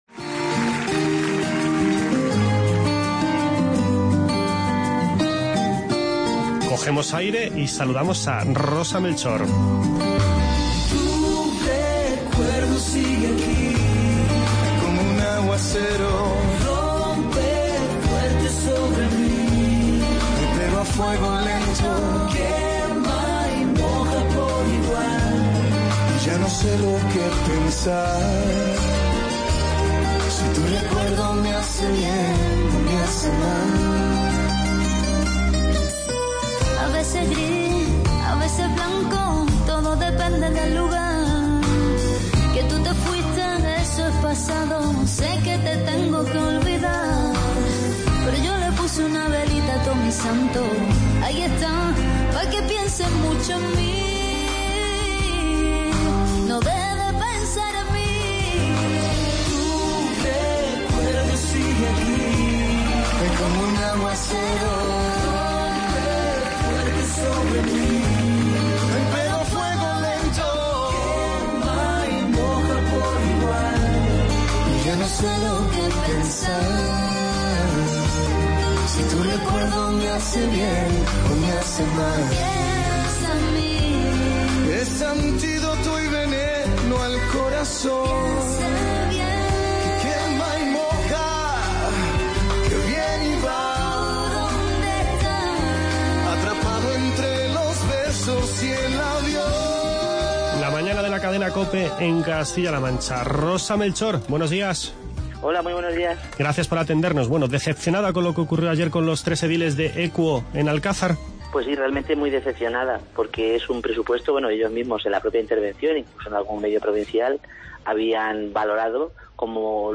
Recordemos que ayer fueron expulsados y se les quitaron sus atribuciones por su voto contrario a la aprobación de los presupuestos municipales de 2016. Charlamos de este asunto con la alcaldesa de Alcázar de San Juan, Rosa Melchor, con la que también hablamos de la Feria de los Sabores que comienza esta misma tarde.
Este fin de semana esta población acoge el XXVIII Campeonato de España de Kuoshu, un evento que comentamos con el primer edil de la localidad, José Javier Martínez.